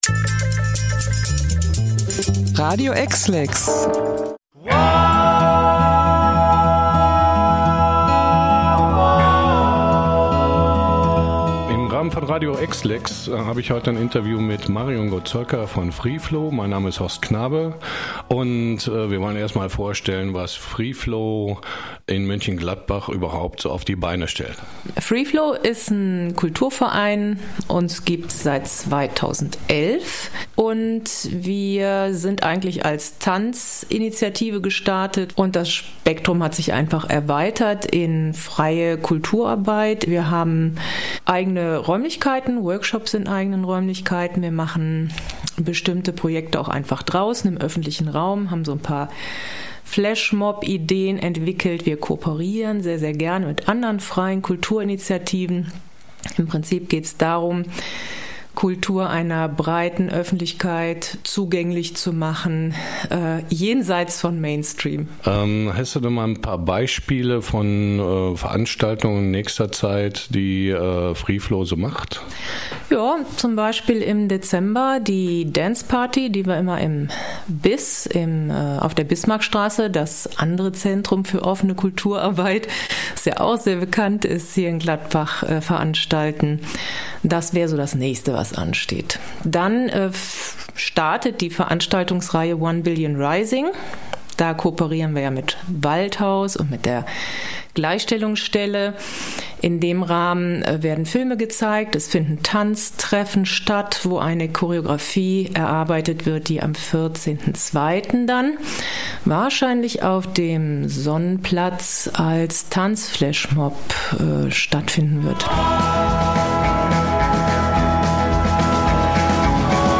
Radio-EXLEX-Interview-Freeflow.mp3